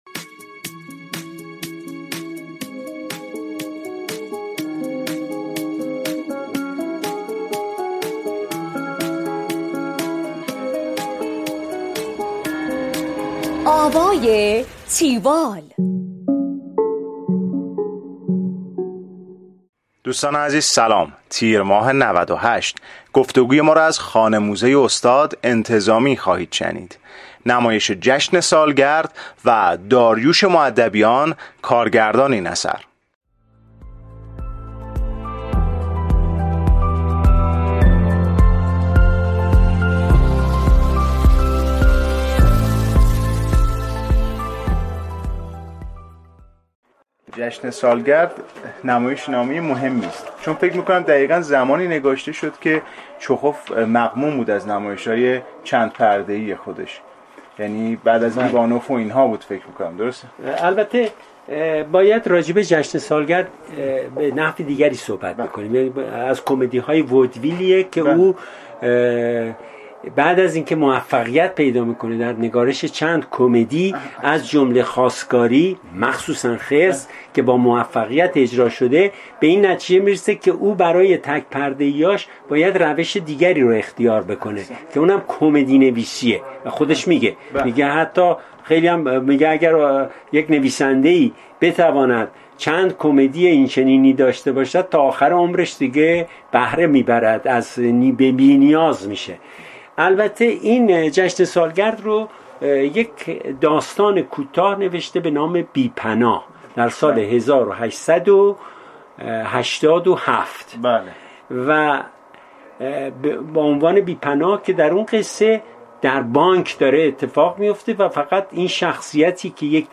گفتگوی